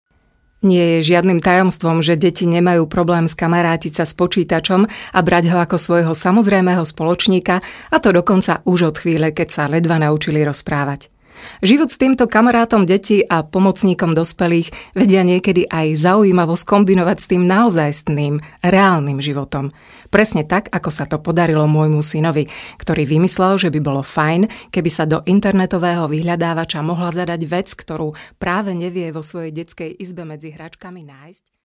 Professionelle slowakische Sprecherin für TV / Rundfunk / Industrie / Werbung.
Sprechprobe: Werbung (Muttersprache):
Professionell slovakian female voice over artist